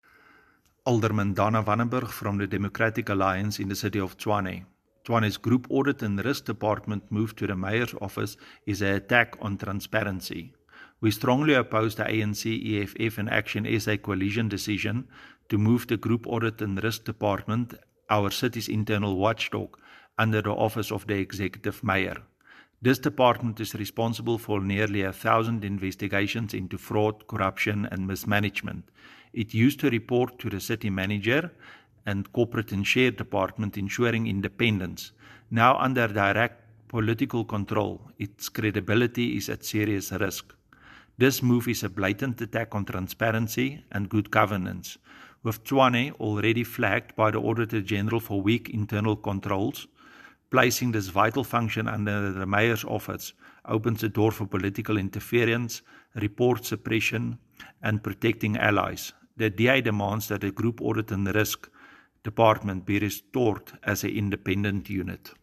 Note to Editors: Please find soundbites in English and Afrikaans by Ald Dana Wannenburg